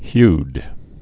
(hyd)